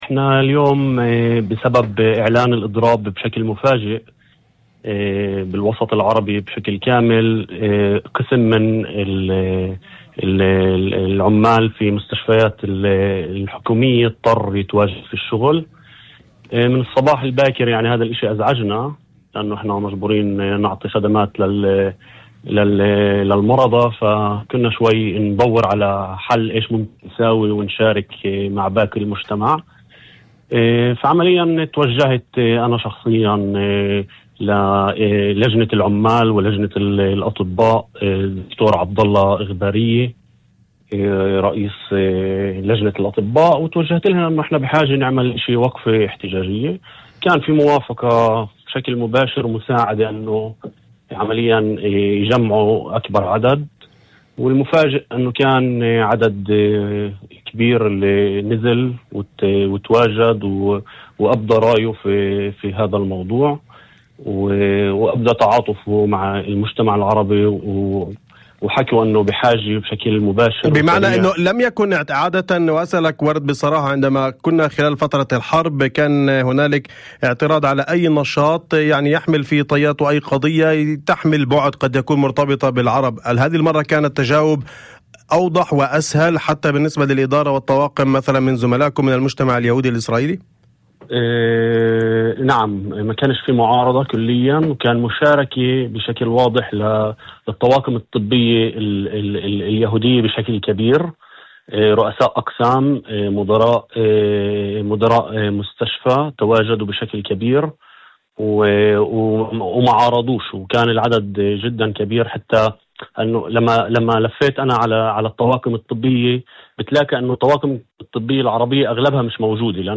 وأضاف في مداخلة هاتفية لبرنامج "الظهيرة"، على إذاعة الشمس، أن هذا الواقع دفعه للتوجه بشكل مباشر إلى لجنة العمال ولجنة الأطباء في المستشفى، لبحث إمكانية تنظيم وقفة احتجاجية داخل المستشفى.